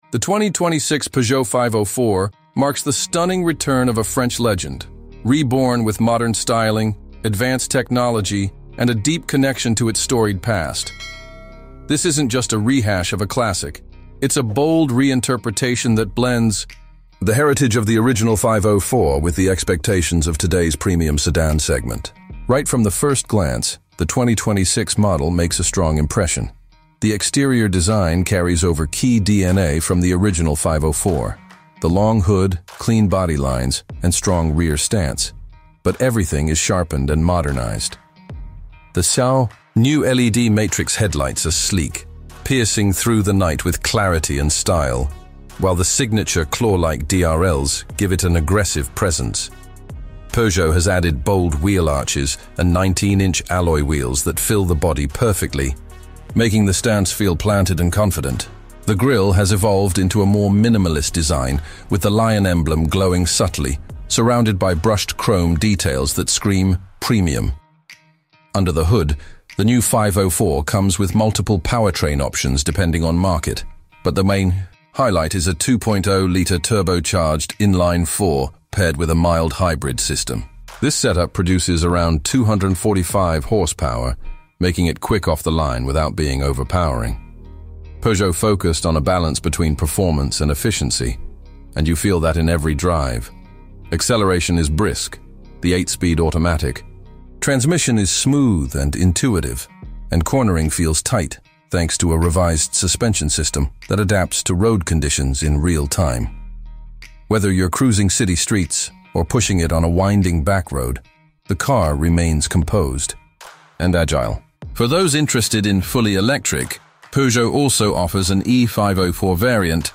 2026 Peugeot 504 Car sound effects free download